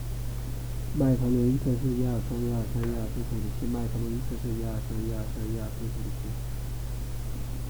编解码器可以录制 LADC 的声音、音量可以、但 RADC 也很低、